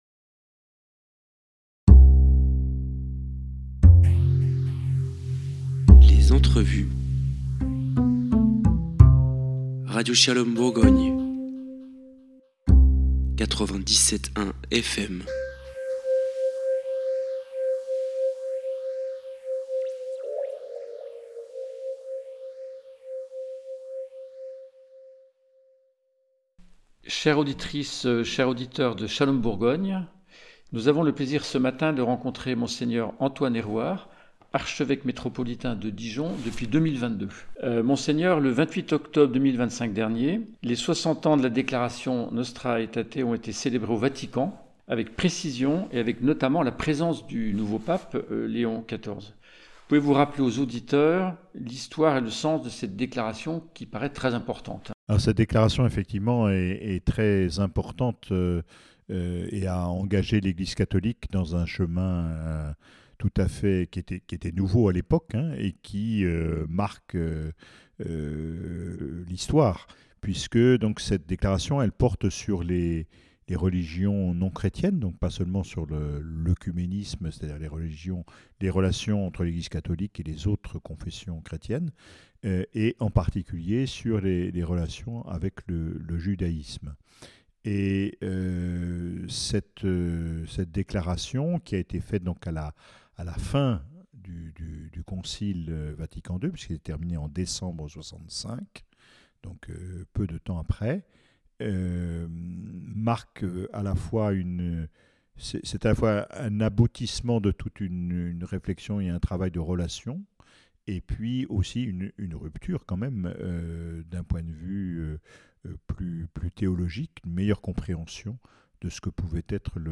« Shalom Bourgogne » a rencontré Monseigneur Antoine Hérouard, àpropos du 60 ième anniversaire de la déclaration conciliaire « NostraAetate », le 28 octobre 2025.
Interview